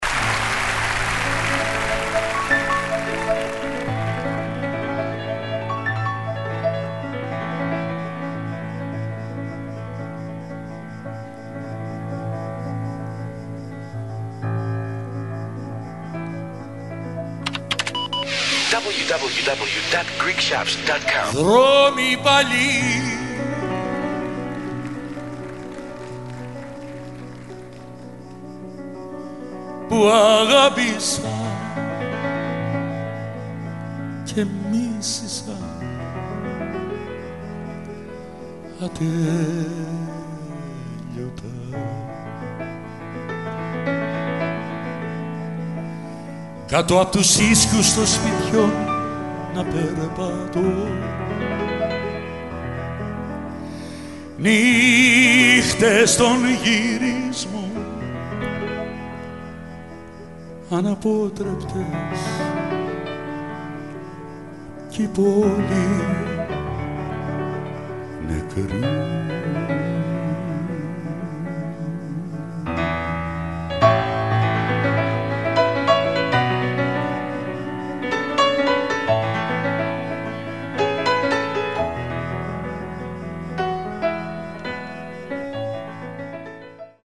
CD 5: Live